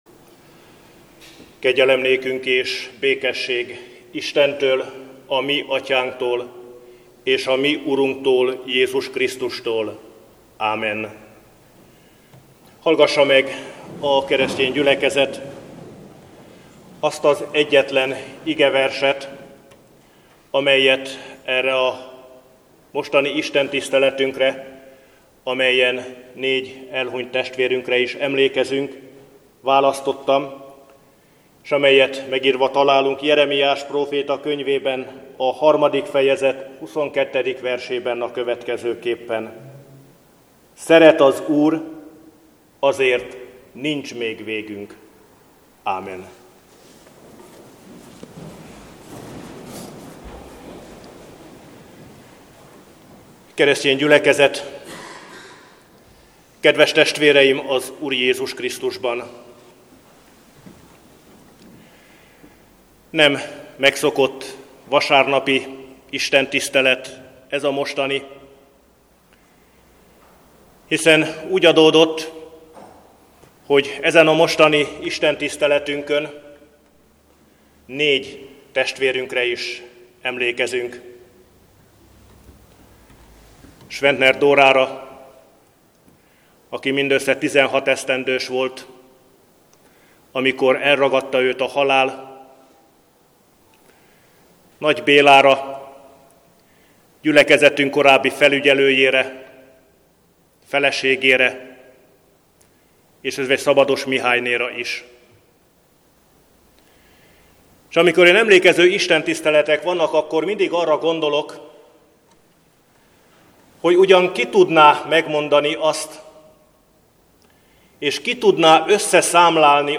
Szentháromság ünnepe után 5. vasárnap
Igehirdetések